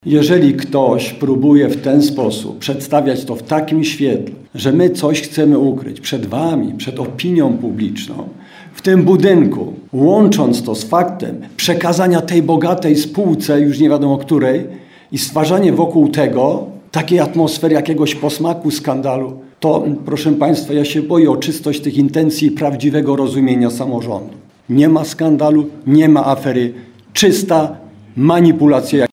Dziś po raz ostatni zebrali się na swojej sesji radni Rady Miejskiej w Czechowicach-Dziedzicach.